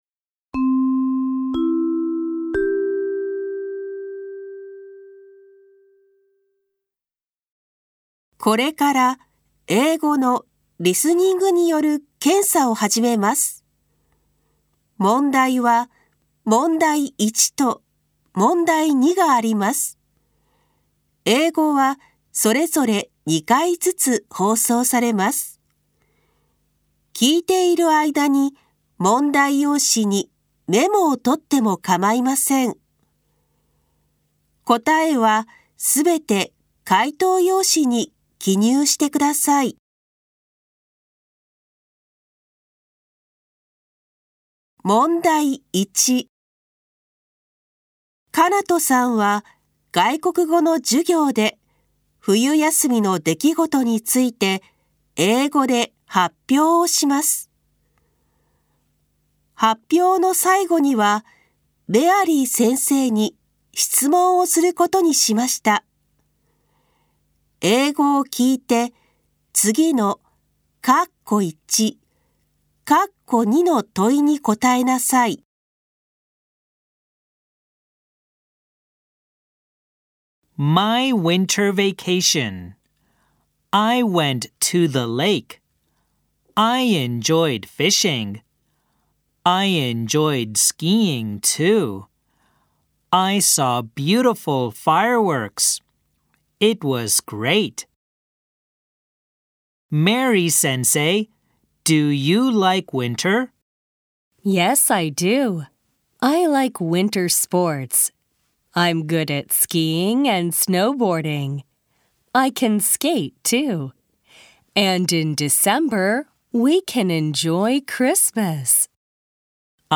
また、これまで行っていた国語の聞き取り問題を廃止し、外国語（英語）の内容を放送によるリスニング問題として出題します。
【外国語（英語）リスニングサンプル問題】